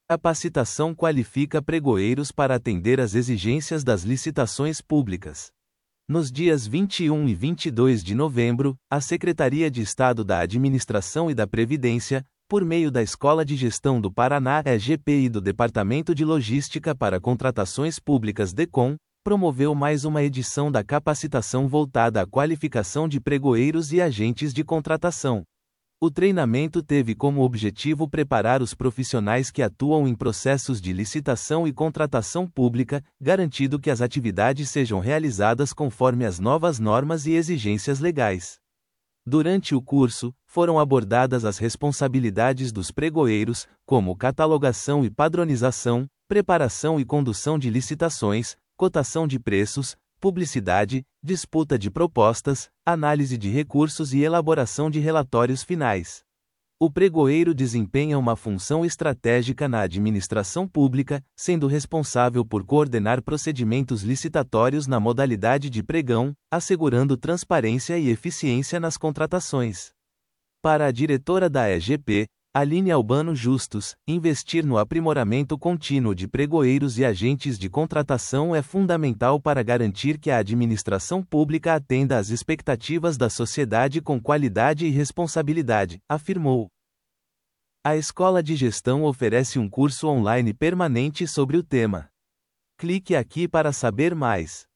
audionoticia_capacitacao_qualifica_pregoeiros.mp3